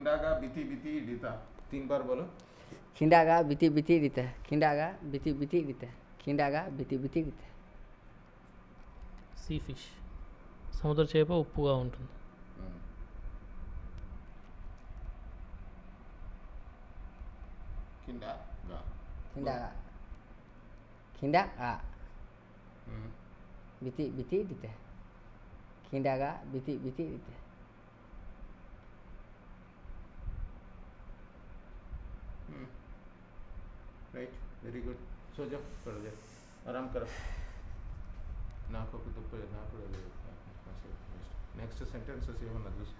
Elicitation of words on fish and related
NotesThis is an elicitation of words on Fish and related